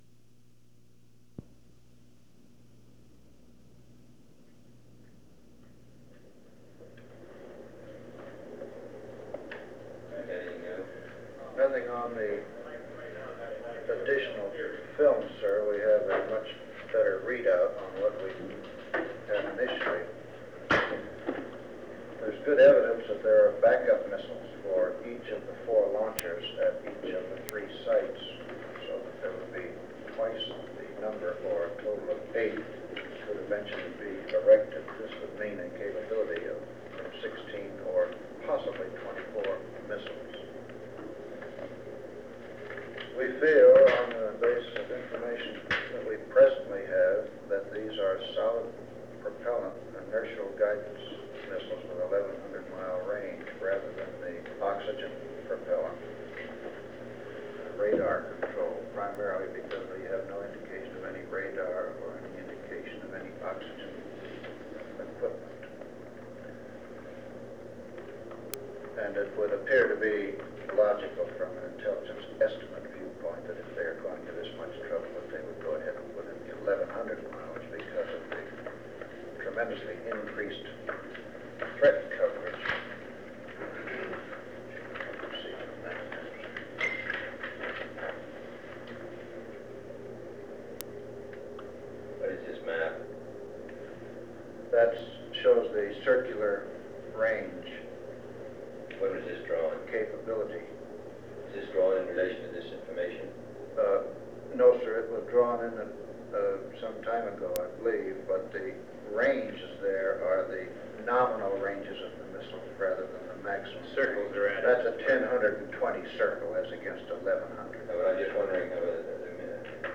Meeting on the Cuban Missile Crisis
Secret White House Tapes | John F. Kennedy Presidency Meeting on the Cuban Missile Crisis Rewind 10 seconds Play/Pause Fast-forward 10 seconds 0:00 Download audio Previous Meetings: Tape 121/A57.